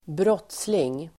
Uttal: [²br'åt:sling]